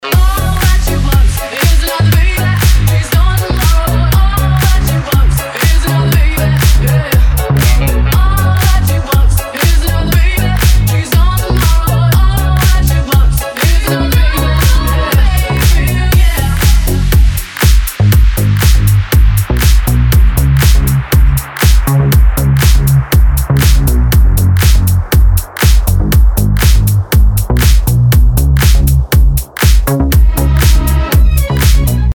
женский вокал
deep house
dance
club
быстрые